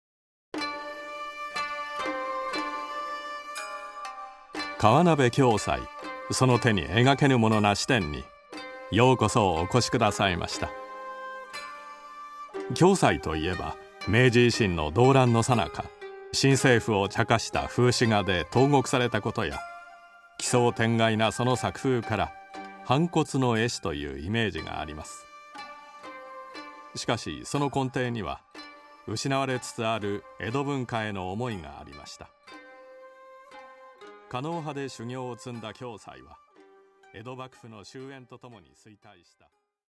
音声ガイドのサンプルは